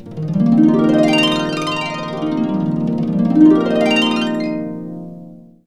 HARP FNX ARP.wav